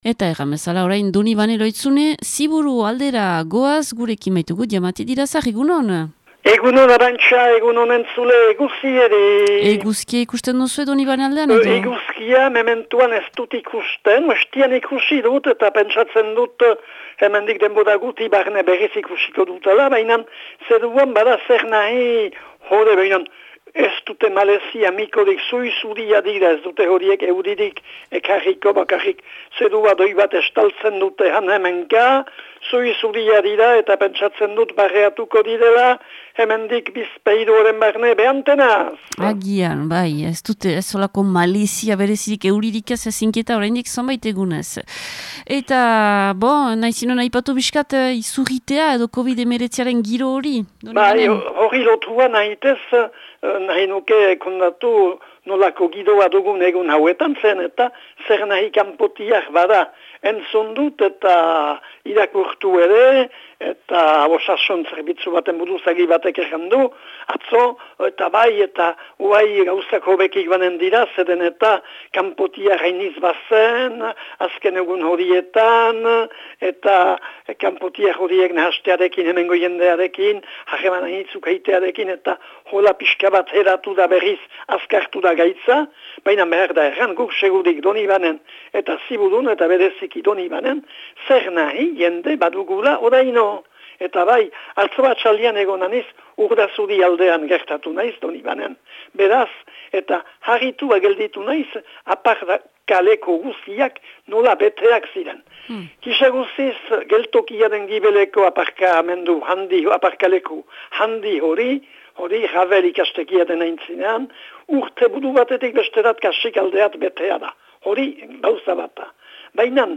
laguntzailearen berriak.